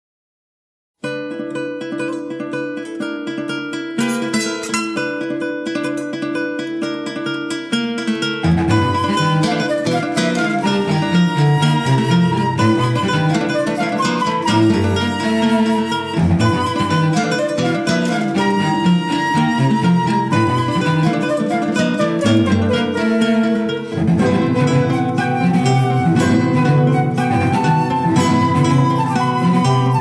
tous deux à la guitare
Violoncelle
flûte